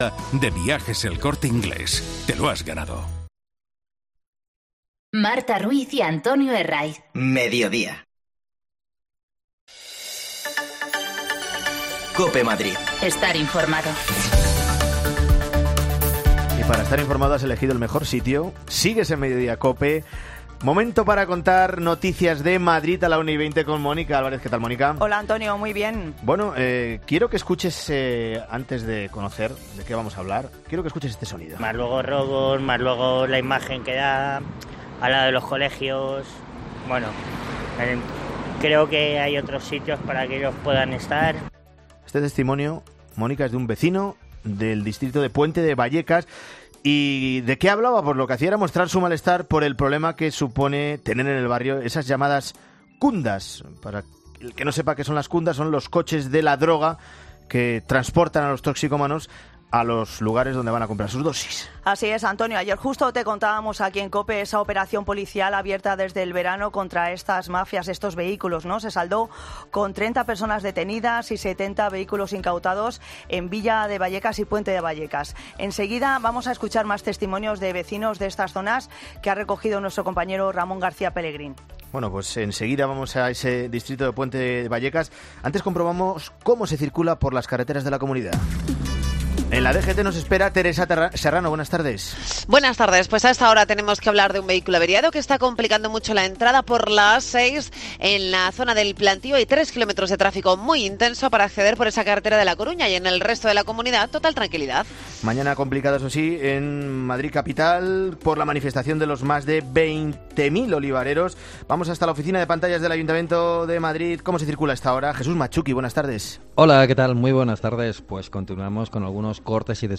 AUDIO: Hablamos con vecinos de Vallecas, hartos de las cundas, los taxis de la droga, que proliferan en esta zona causando muchos problemas.